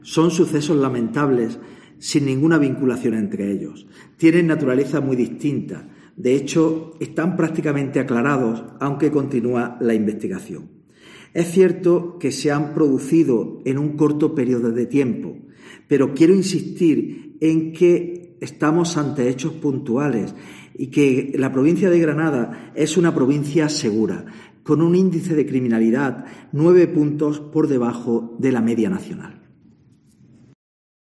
José Antonio Montilla, subdelegado del Gobierno